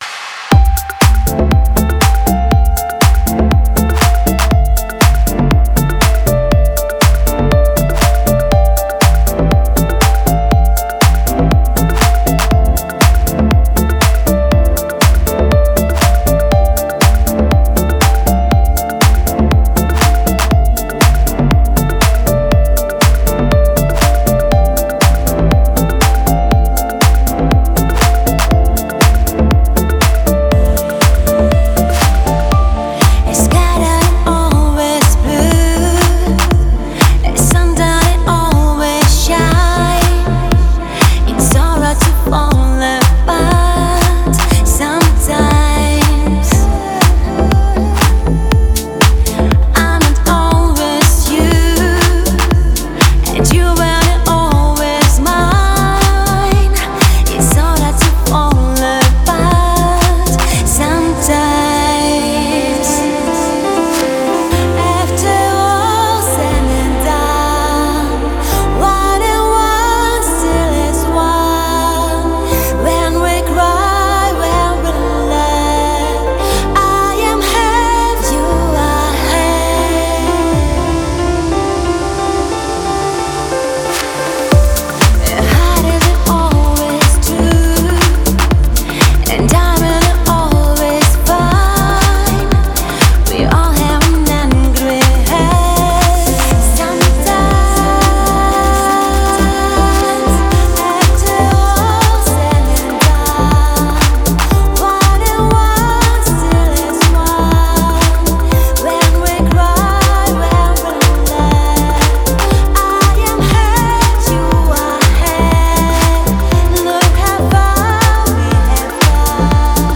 ремейки , каверы